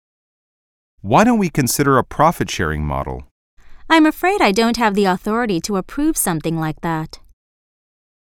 實境對話